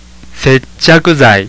有聲發音